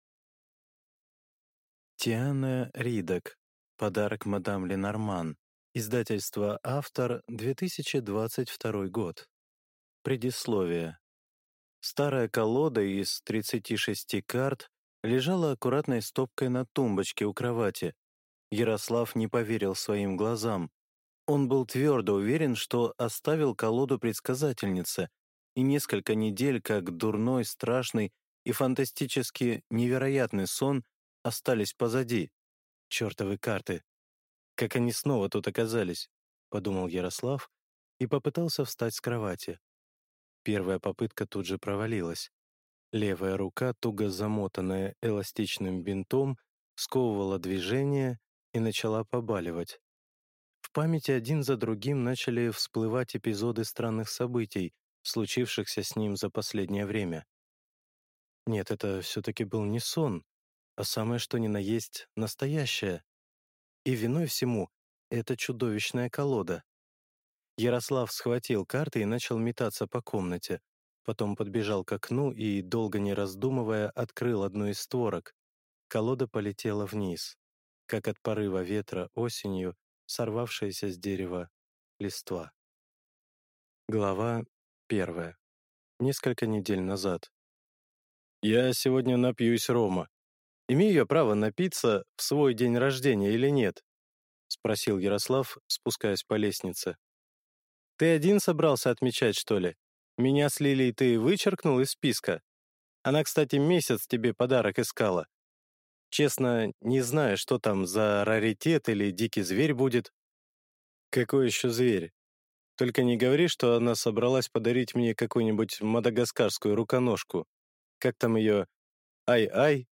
Аудиокнига Подарок мадам Ленорман | Библиотека аудиокниг